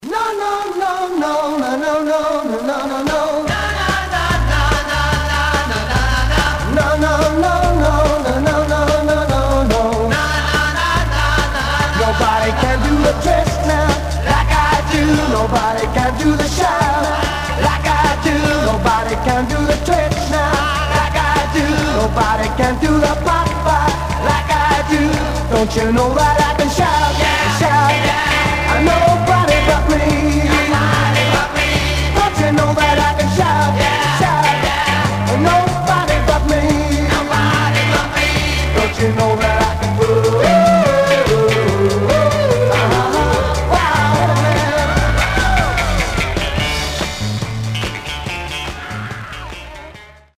Surface noise/wear Stereo/mono Mono
Garage, 60's Punk